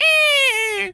bird_large_squawk_02.wav